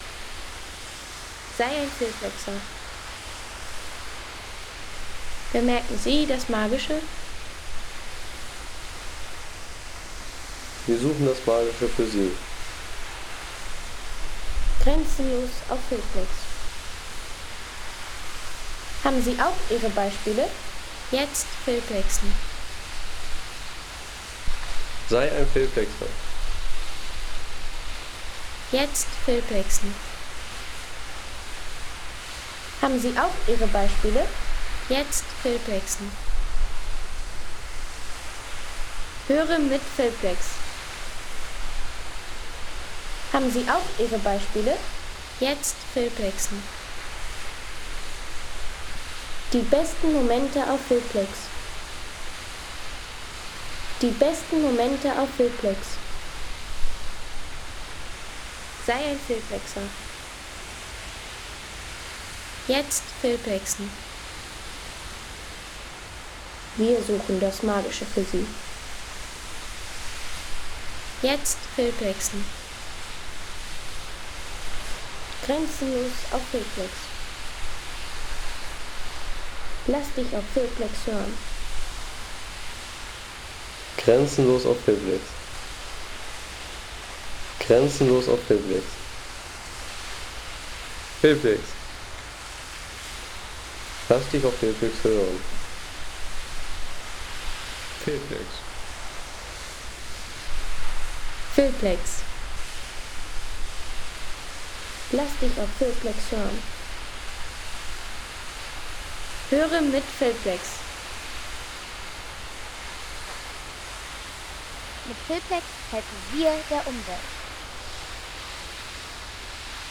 Schleierwasserfall in Hintertux
Landschaft - Wasserfälle